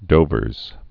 (dōvərz)